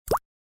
Звуки бульк
Бульк звук в воду